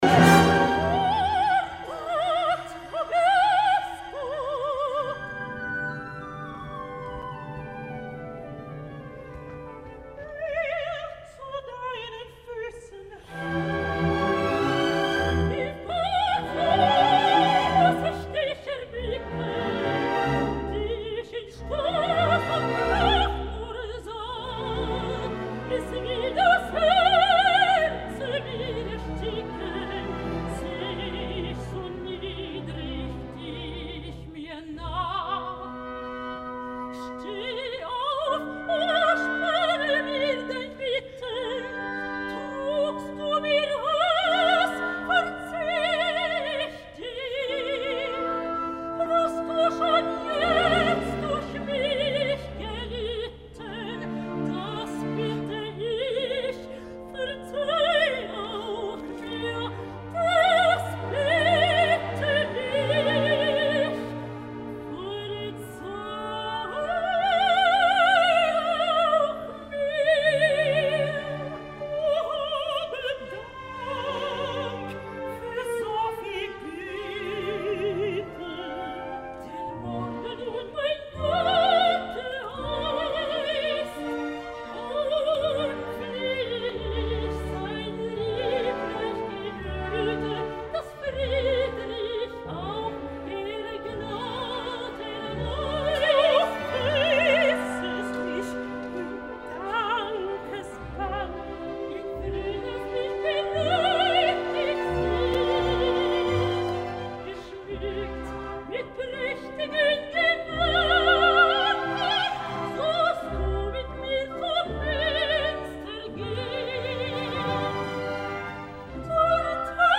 © DR Si vous n'entendez rien au bout de quelques secondes, essayez en cliquant sur le lien suivant : lien suivant Lohengrin, Richard Wagner, Opera de Paris, juin 2007, mes. Robert Carsen dir. Valery Gergiev Ortrud : Waltraud Meier